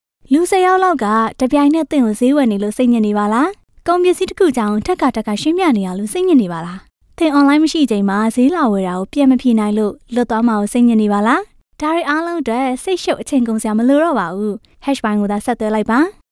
广告【清新自然】